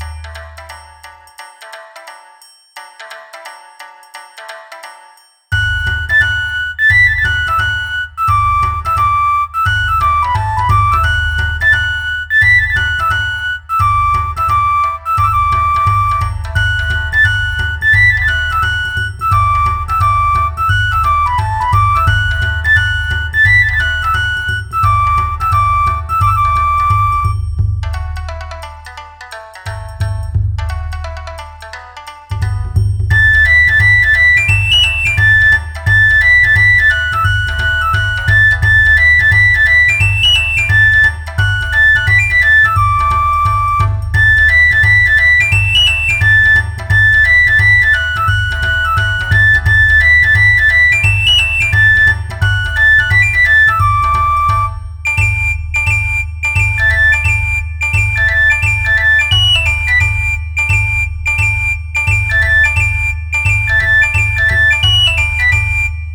陽気な三味線と篠笛のお囃子です
無限ループ明るい和風